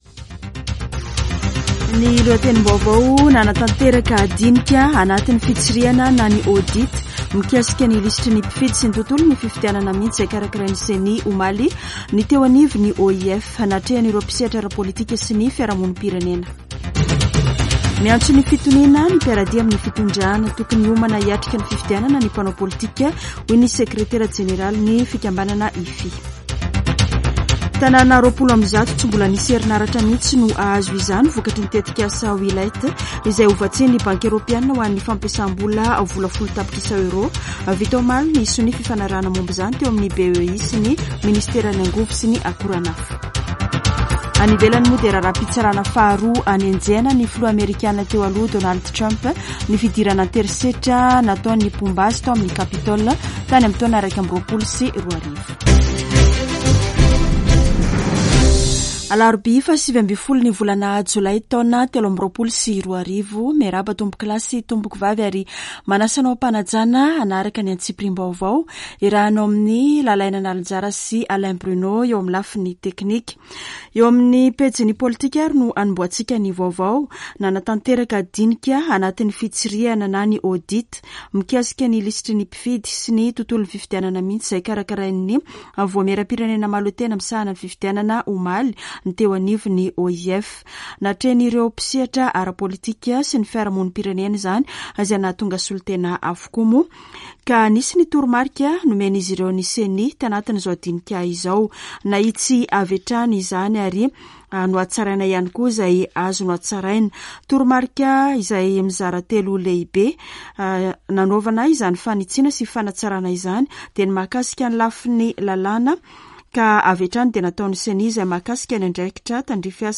[Vaovao maraina] Alarobia 19 jolay 2023